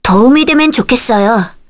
Leo-Vox_Skill2_kr.wav